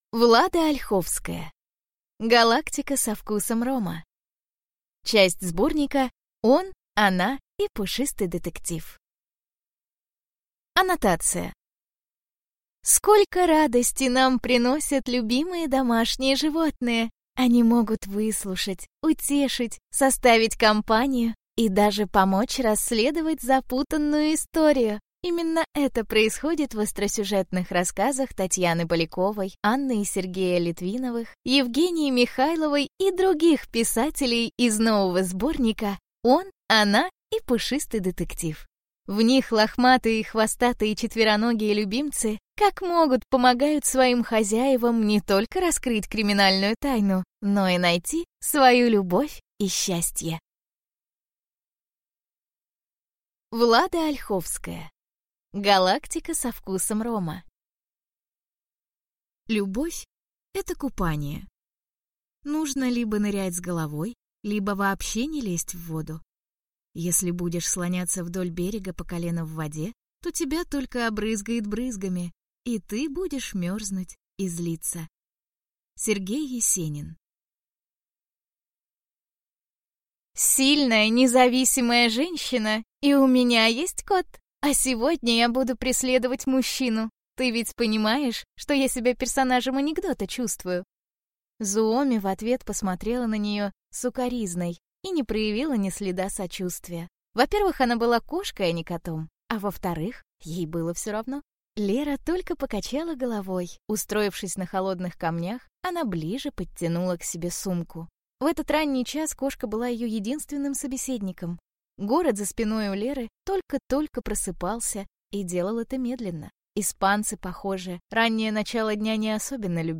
Аудиокнига Галактика со вкусом рома | Библиотека аудиокниг